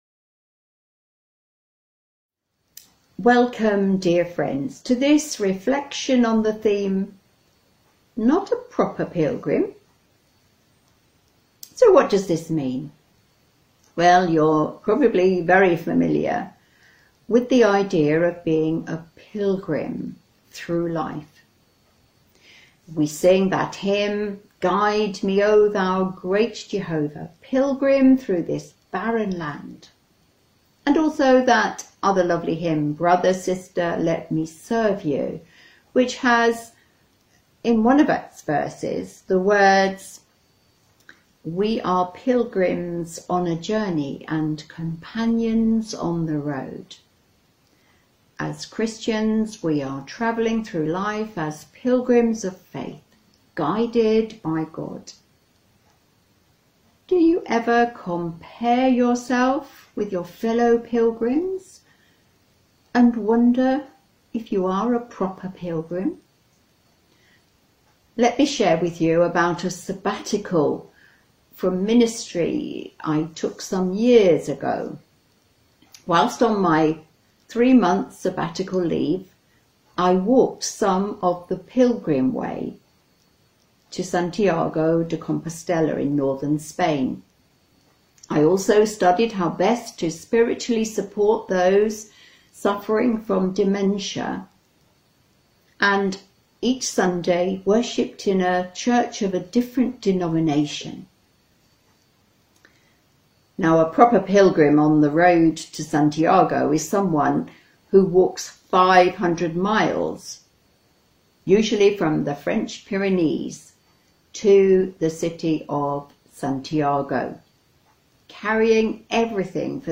Worship at Home